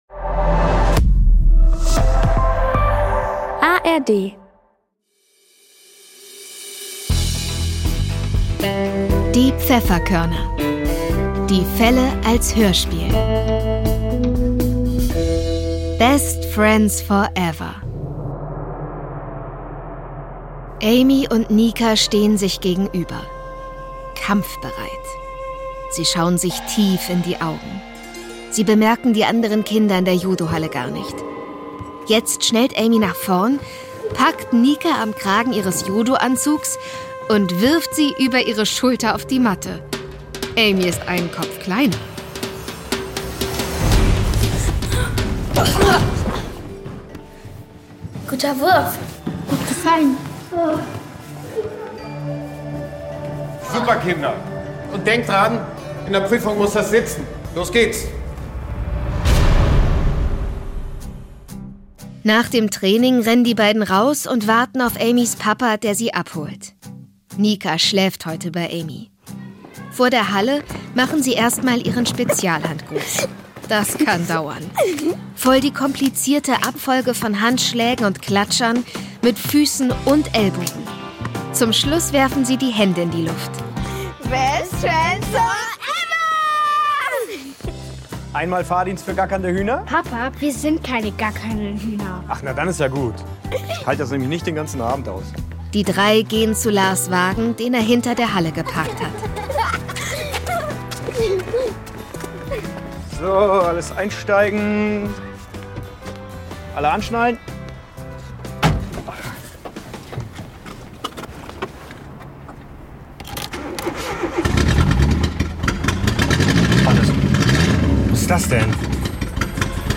Wie gefallen Dir die Hörspiele der Pfefferkörner?